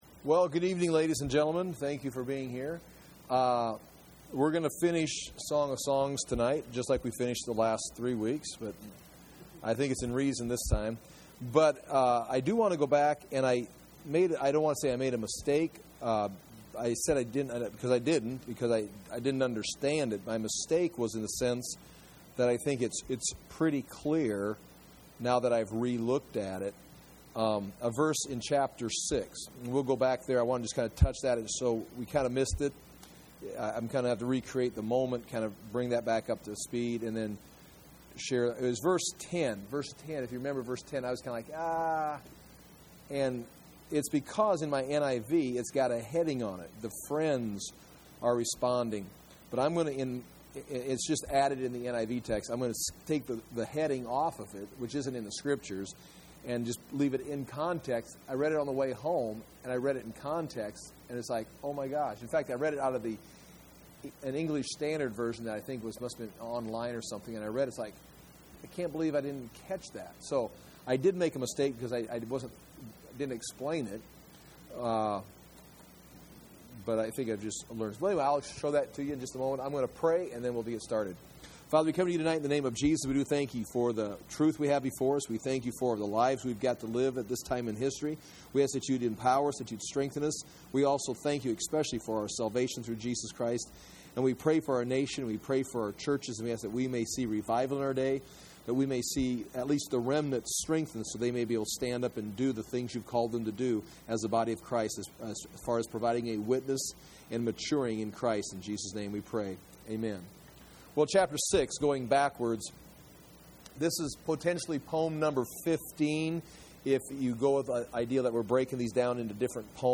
Song of Songs - verse by verse Bible teaching audio .mp3, video, notes, maps, lessons for the Book of Song of Songs or Song of Solomon